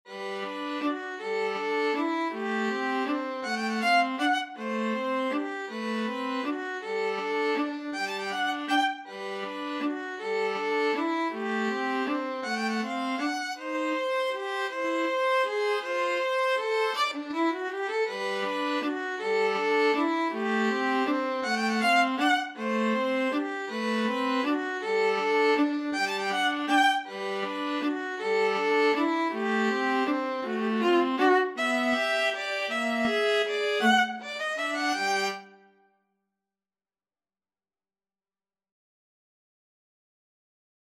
3/4 (View more 3/4 Music)
Allegro grazioso =160 (View more music marked Allegro)
Classical (View more Classical Violin-Viola Duet Music)